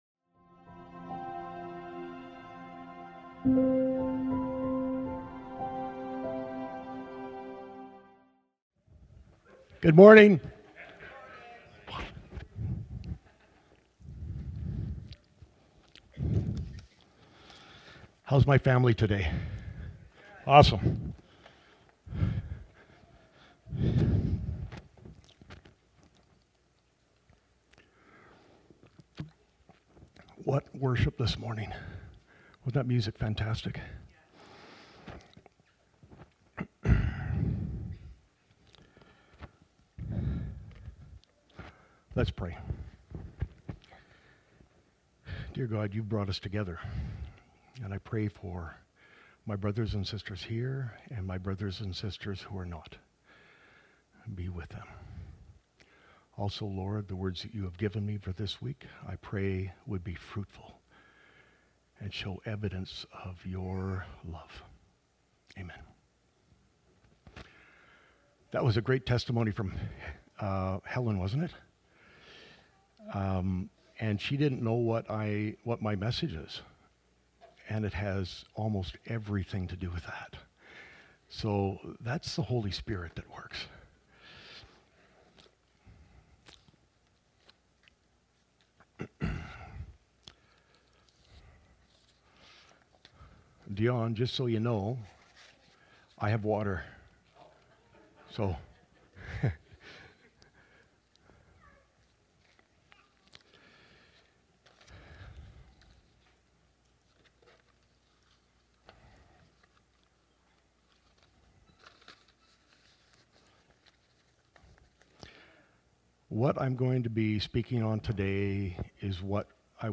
Sermons | Hillside Community Church